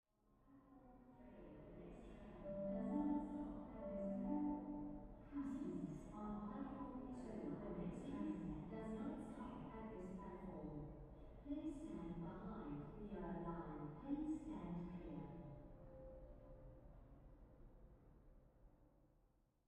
announcements.ogg